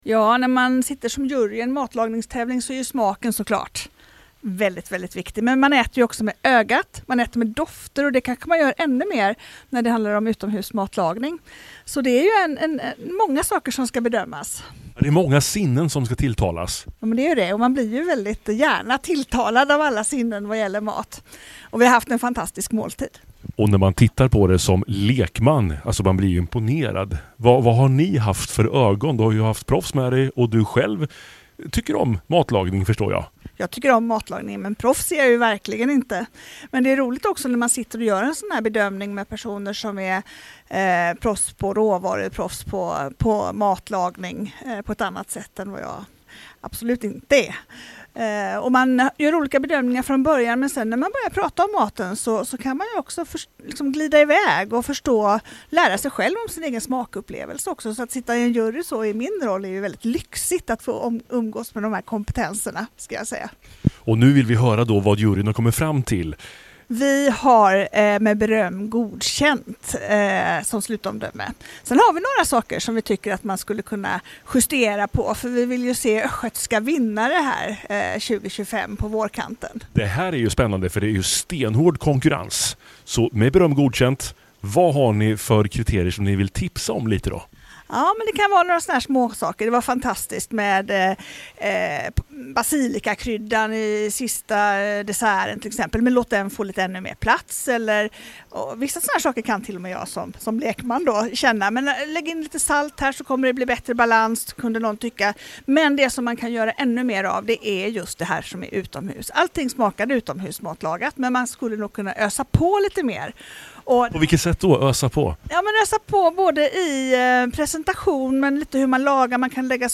Här kan du lyssna till när tf landshövding Ann Holmlid läser upp juryns motivering: